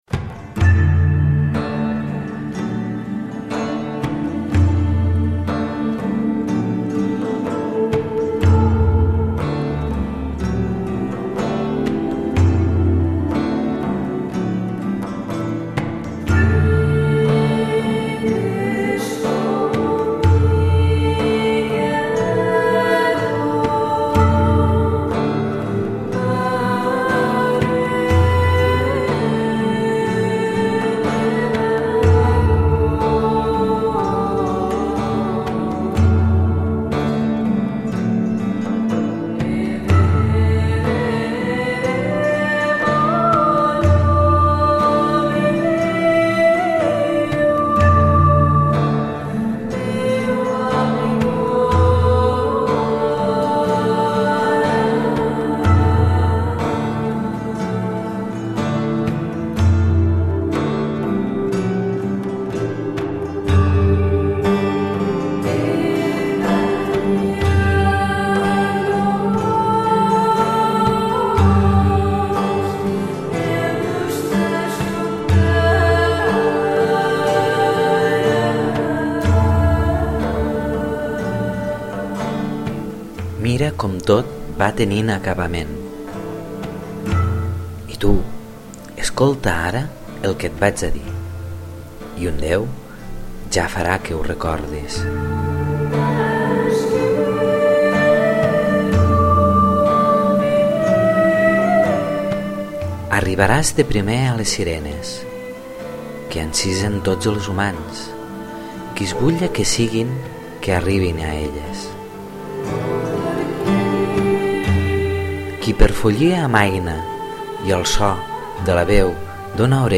I la raó és que he deixat còrrer la música al principi per tal que pugueu xalar escolant les magnífiques veus del grup Stellamara de la cançó anomenada Zèfir (com el déu grec).